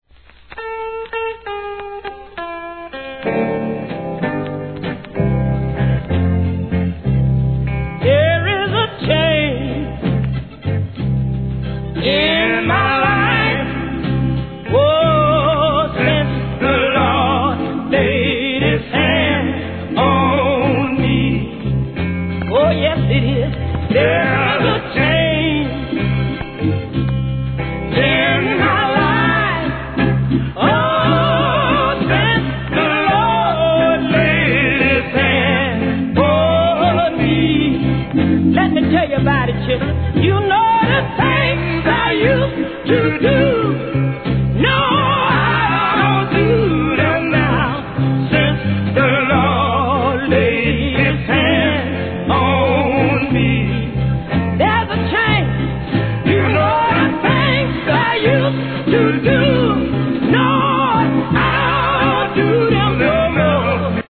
SOUL/FUNK/etc...
誰もが聴き入ってしまうほどの歌唱力と狂いの無いコーラス・ワークは一聴の価値有り!!